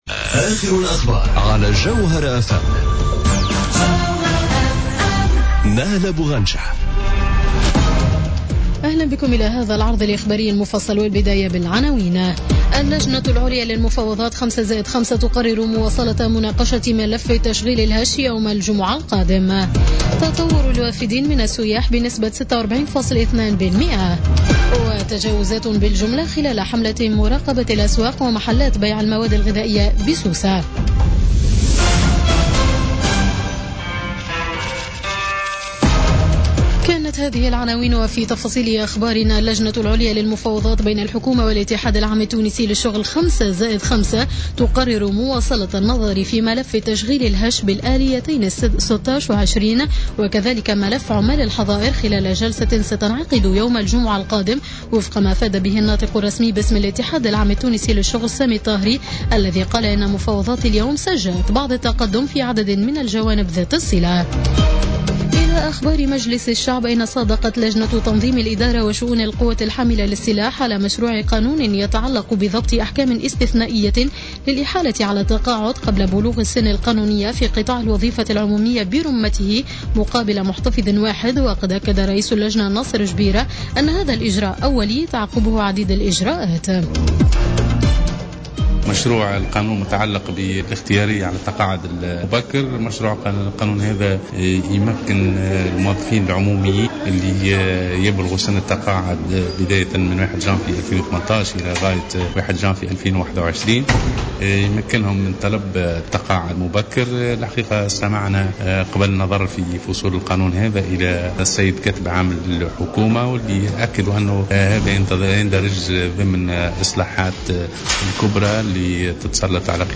نشرة أخبار السابعة مساء ليوم الخميس غرة جوان 2017